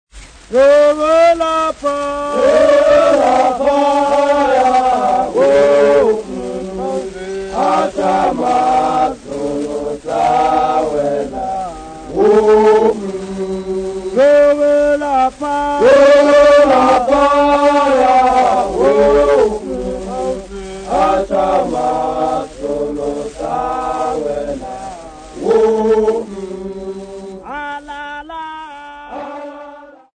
A recording of an unaccompanied traditional song by Hlubi men. This song was recorded at an unspecified location field recording, 1948.]